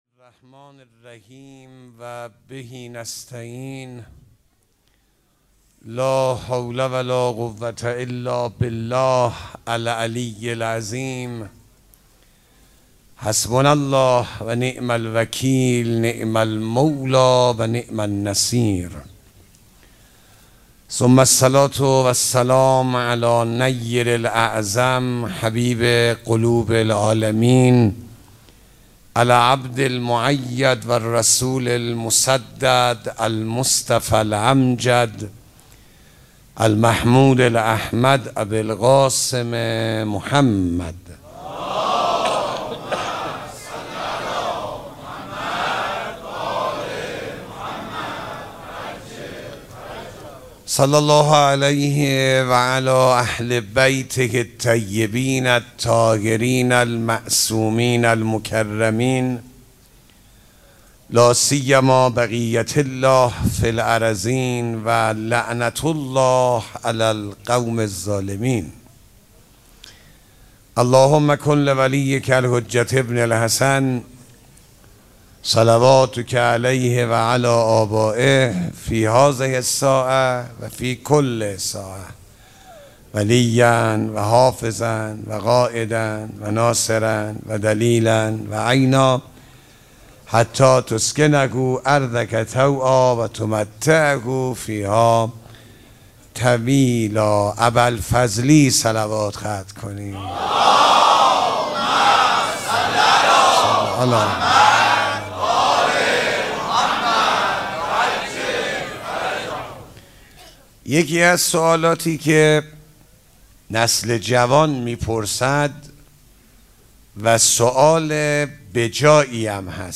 سخنرانی
مراسم جشن ولادت سرداران کربلا (شب دوم)
sokhanrani.mp3